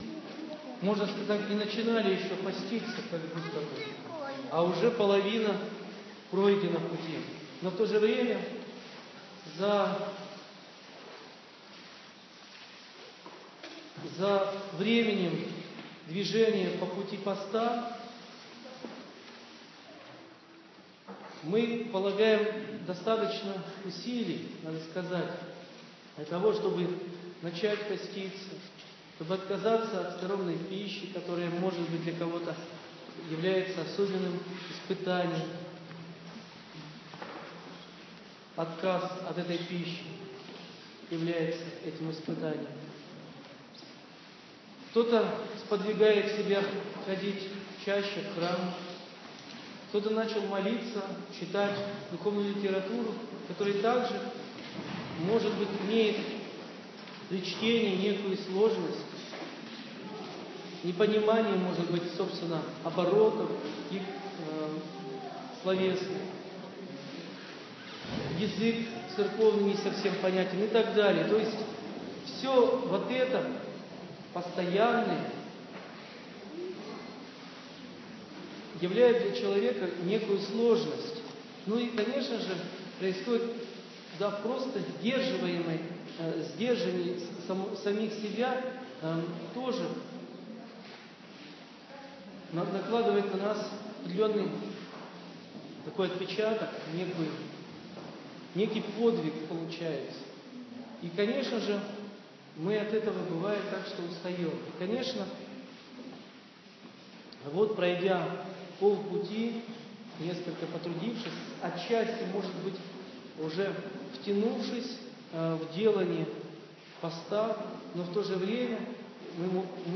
Слово
после Богослужения 3 апреля в Крестопоклонную неделю Великого Поста